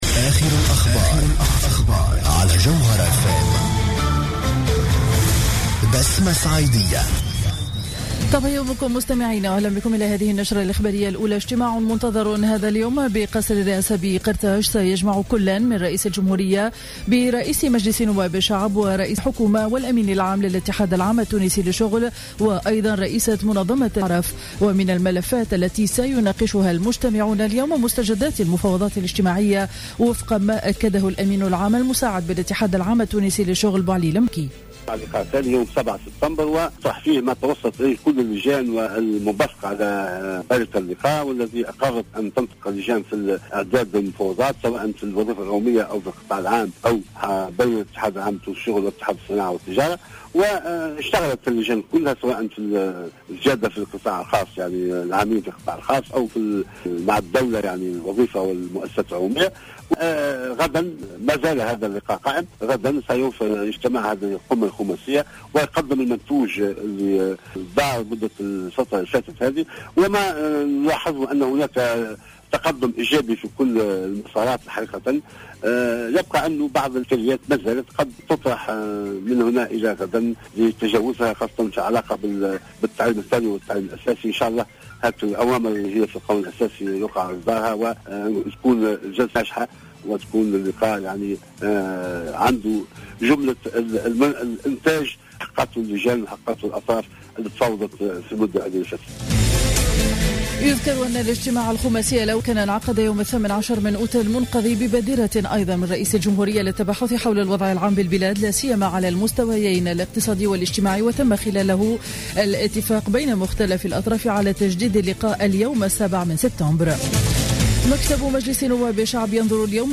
نشرة أخبار السابعة صباحا ليوم الإثنين 7 سبتمبر 2015